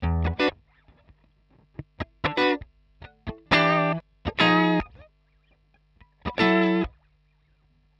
120_Guitar_funky_riff_E_2.wav